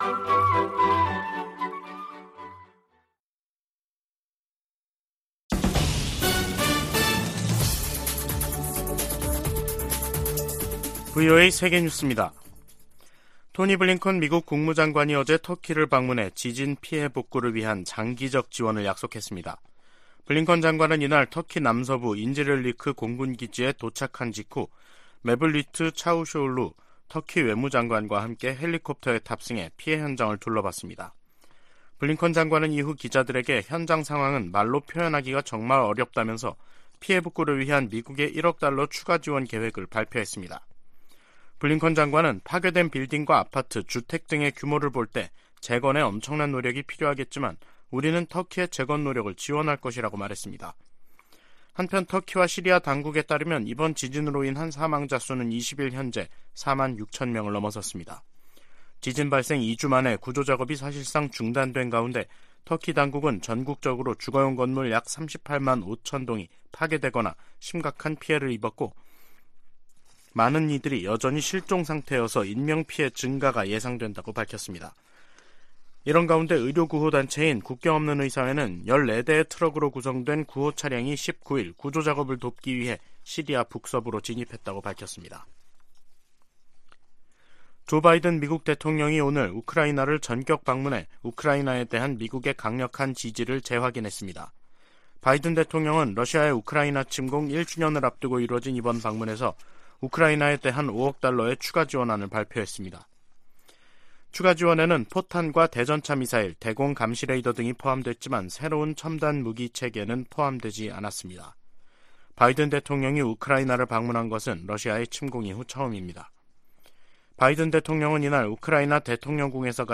VOA 한국어 간판 뉴스 프로그램 '뉴스 투데이', 2023년 2월 20일 2부 방송입니다. 북한이 ‘화성-15형’ 대륙간탄도미사일(ICBM)을 쏜 지 이틀 만에 평안남도 숙천 일대에서 동해상으로 초대형 방사포를 발사했습니다. 미국과 한국, 일본 외교장관이 긴급 회동을 갖고 북한의 ICBM 발사를 규탄하면서 국제사회의 효과적인 대북제재 시행을 촉구했습니다. 한국 정부는 북한의 ICBM 발사 등에 대해 추가 독자 제재를 단행했습니다.